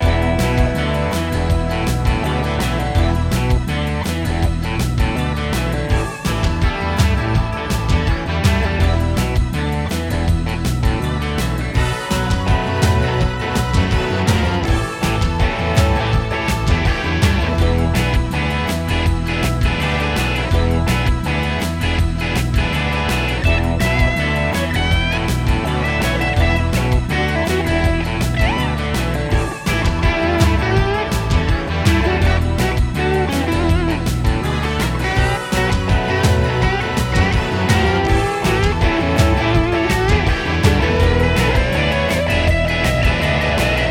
twist
melodía